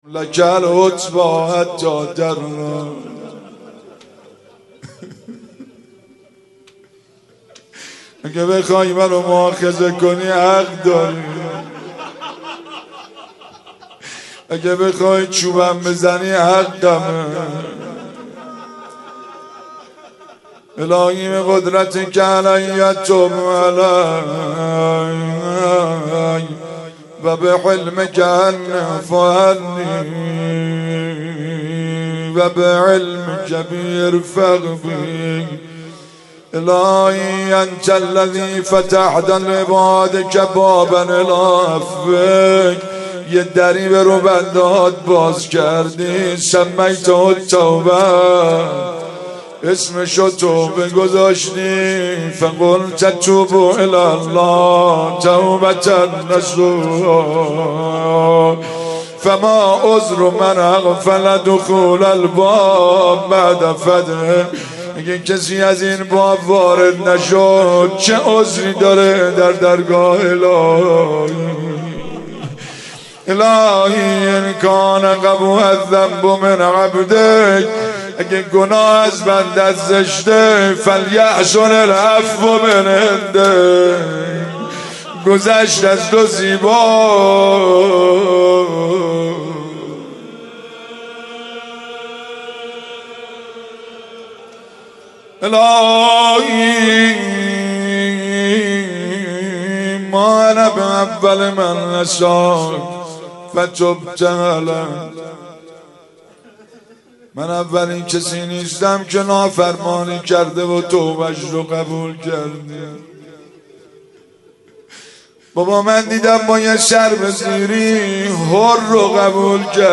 مناجات التائبین
شب سوم رمضان 95